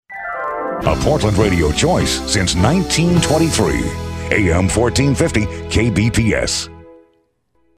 They have in the past, graciously donated their services to provide KBPS with on-air imaging.